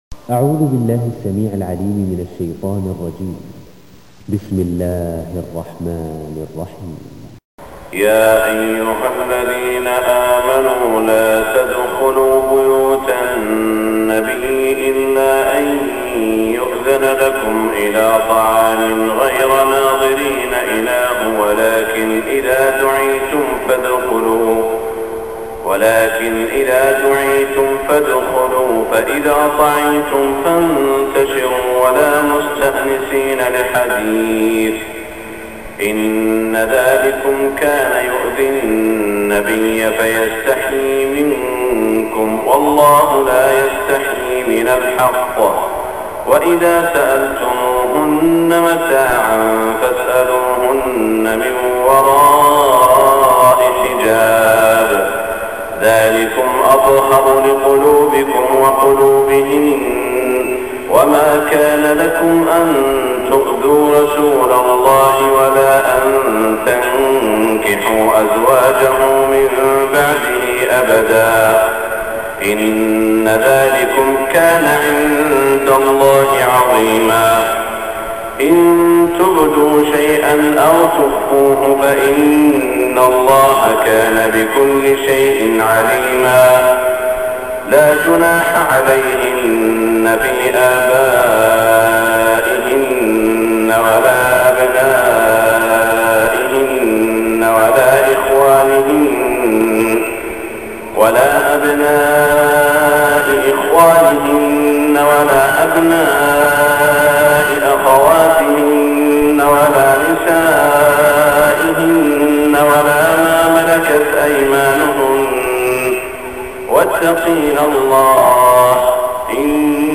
صلاة الفجر 1425هـ من سورة الأحزاب > 1425 🕋 > الفروض - تلاوات الحرمين